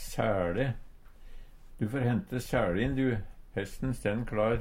sæLe - Numedalsmål (en-US)